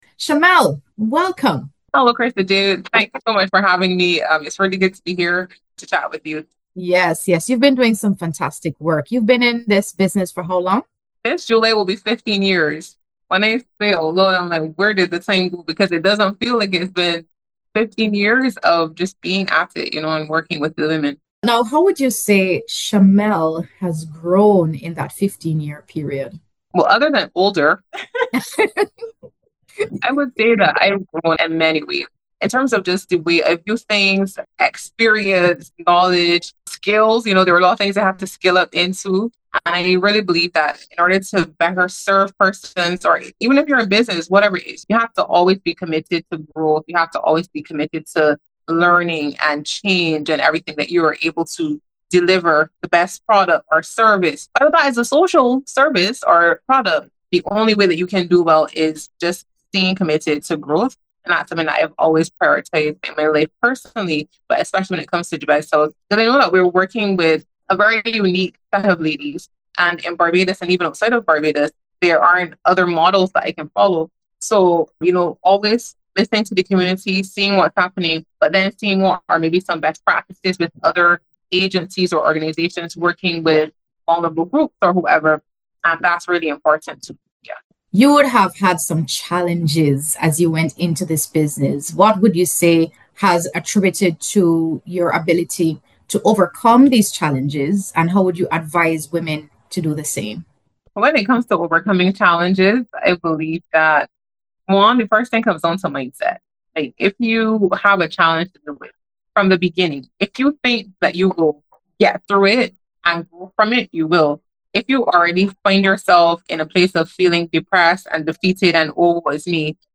A conversation on leadership in journalism, and her journey in building a legacy in media.